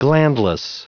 Prononciation du mot glandless en anglais (fichier audio)
Prononciation du mot : glandless